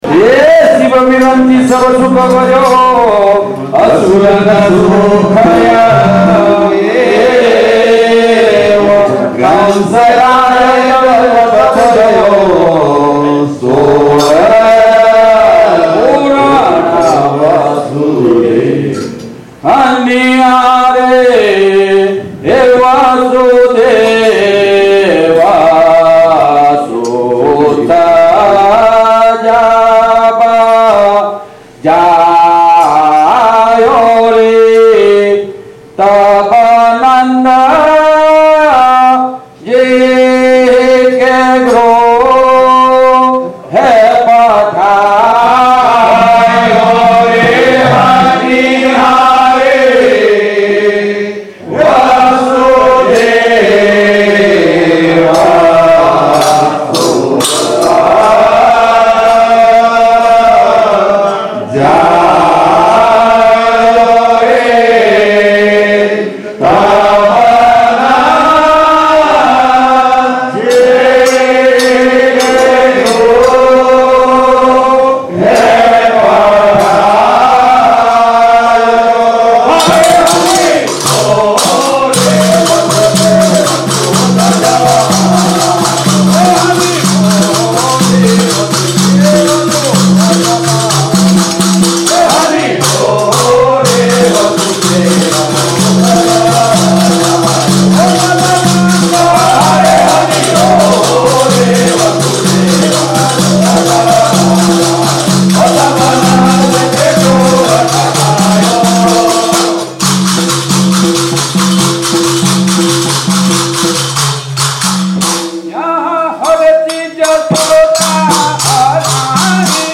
નાદબ્રહ્મ પદ - ૬૨૮, રાગ - સામેરી રાસ શિવ વિરંચિ સબ સુખ ભર્યો Nādbrahma ...